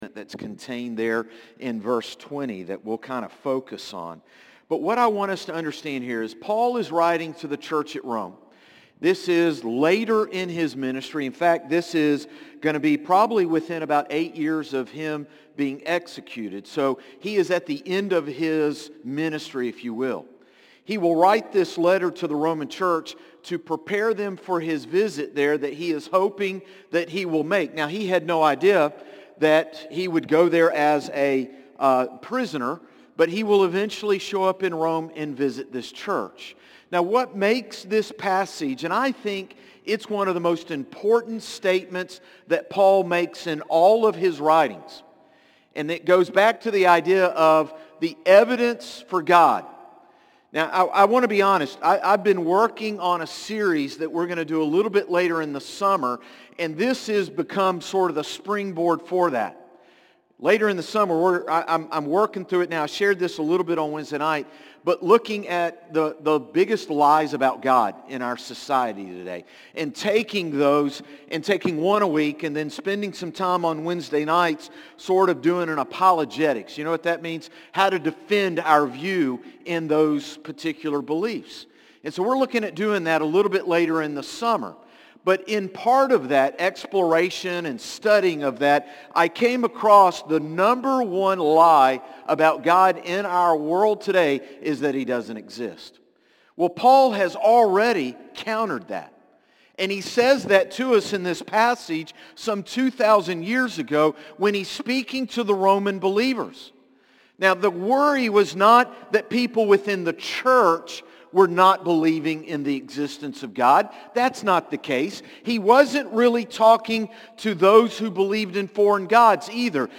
Sermons - Concord Baptist Church
Morning-Service-5-4-25.mp3